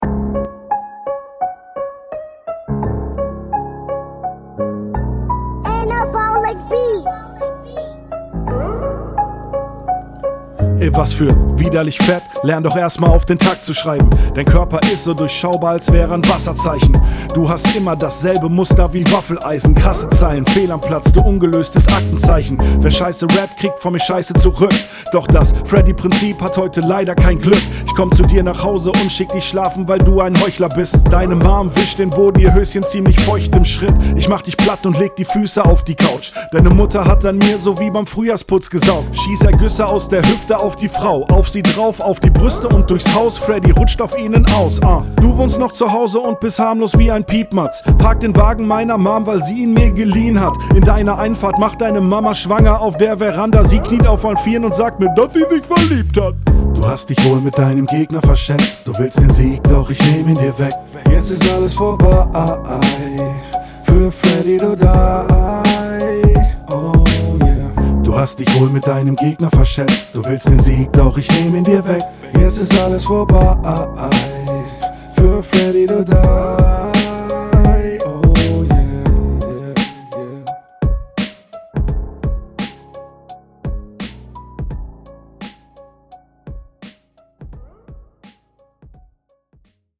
Stimmlich recht unbeteiligt, flowtechnisch sicher und textlich …